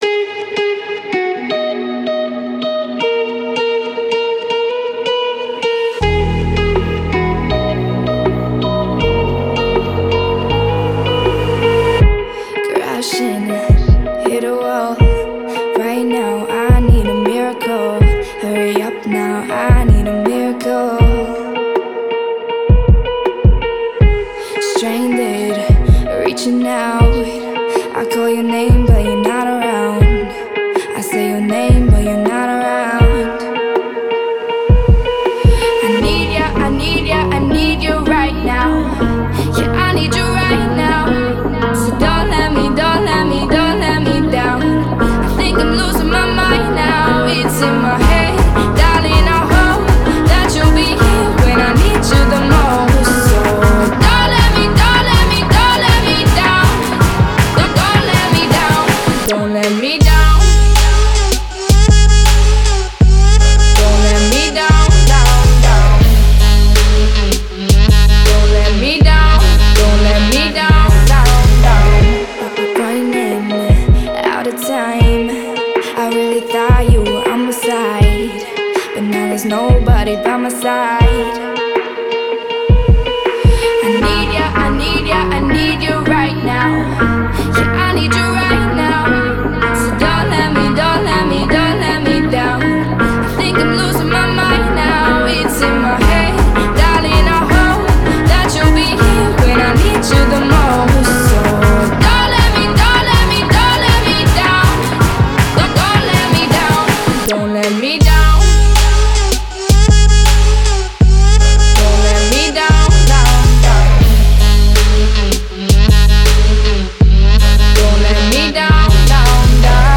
Genre:Indie-pop & Pop